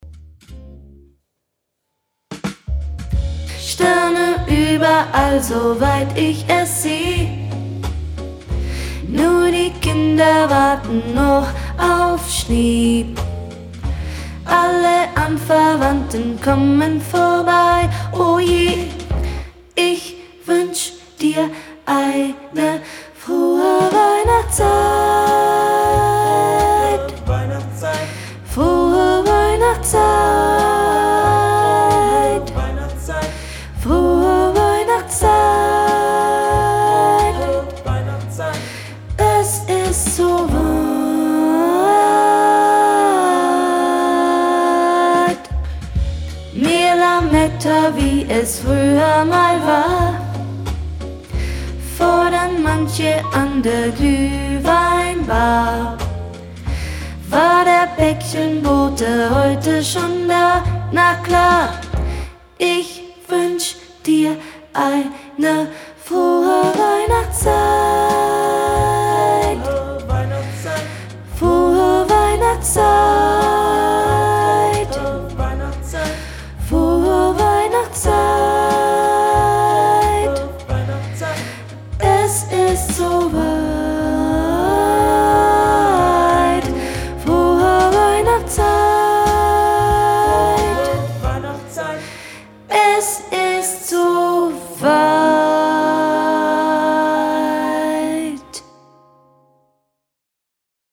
Übungsaufnahme Sopran
Frohe_Weihnachtszeit - Sopran.mp3